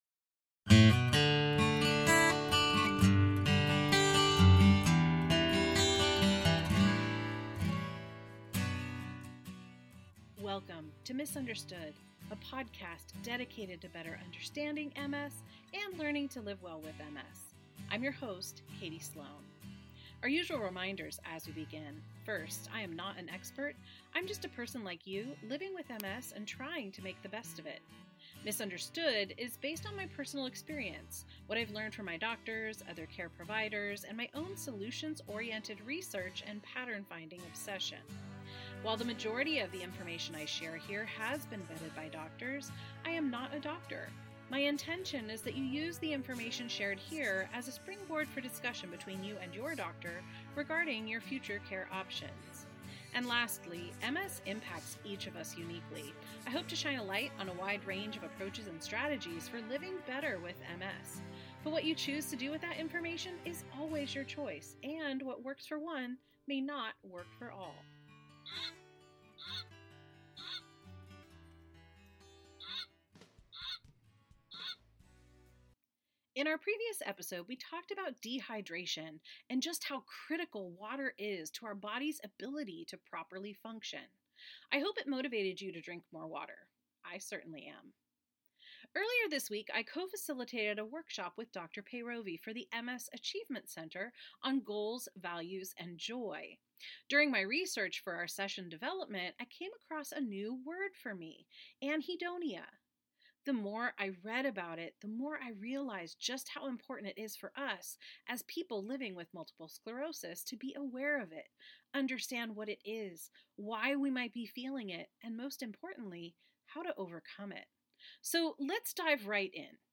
1 KT Sermon: We Want to See Jesus 32:20